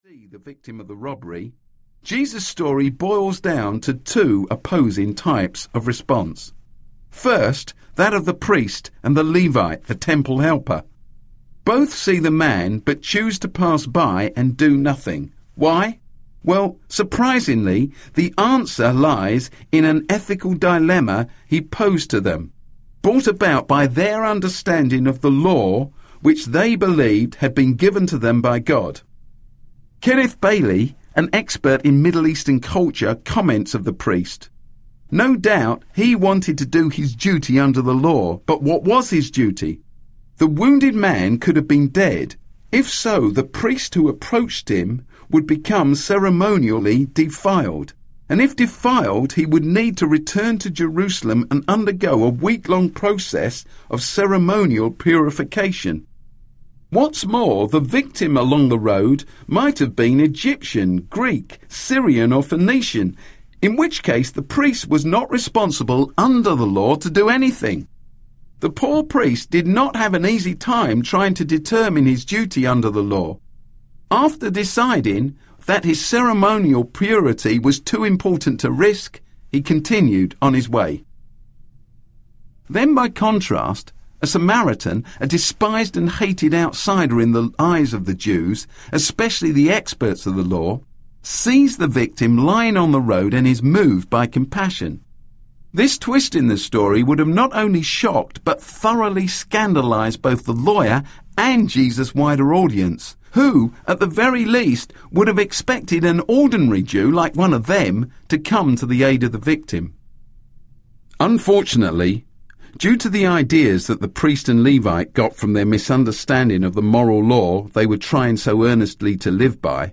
Different Eyes Audiobook
5.15 Hrs. – Unabridged